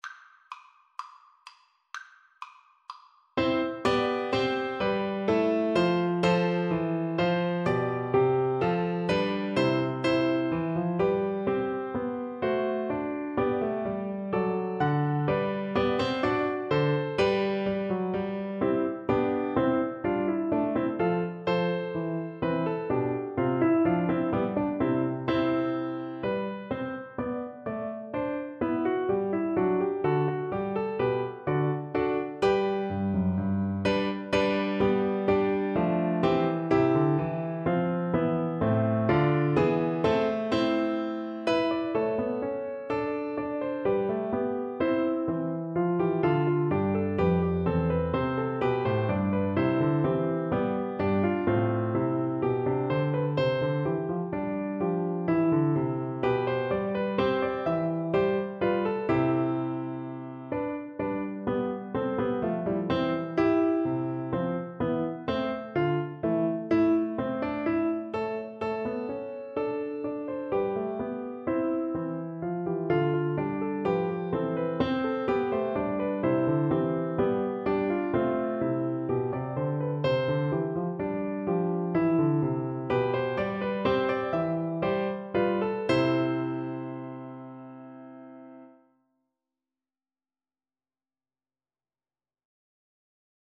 Play (or use space bar on your keyboard) Pause Music Playalong - Piano Accompaniment Playalong Band Accompaniment not yet available transpose reset tempo print settings full screen
C major (Sounding Pitch) (View more C major Music for Flute )
Allegretto = 126
Classical (View more Classical Flute Music)